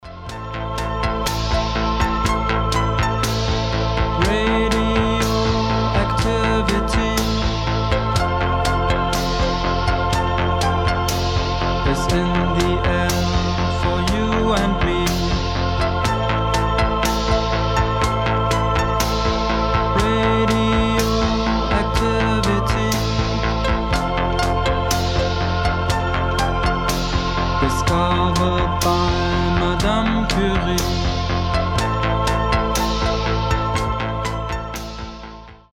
• Качество: 320, Stereo
атмосферные
Electronic
мрачные
experimental